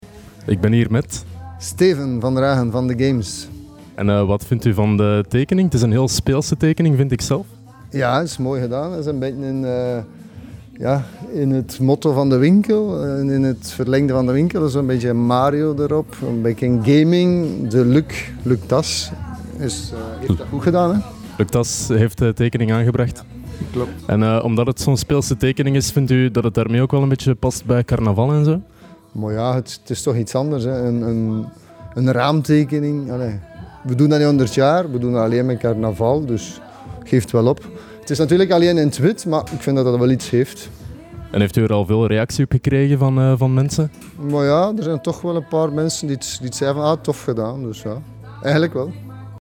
interview-The-Games_raamtekening.mp3